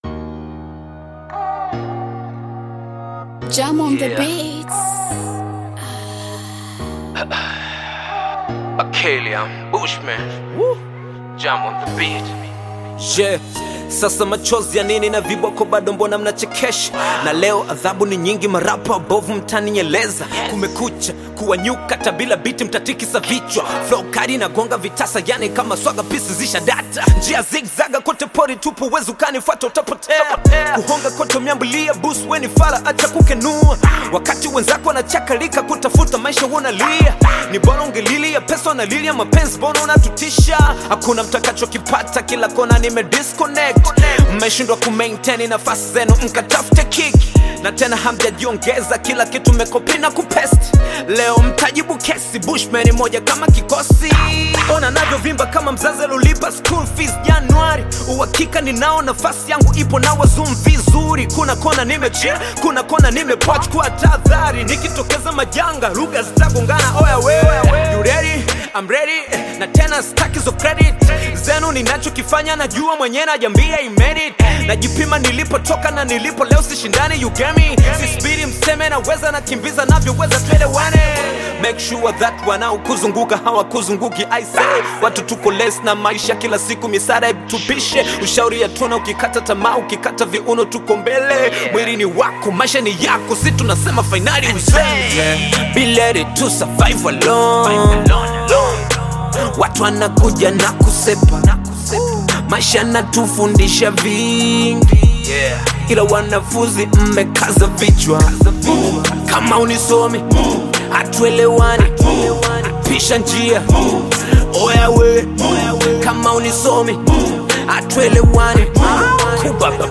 Bongo Flava music track
This catchy new song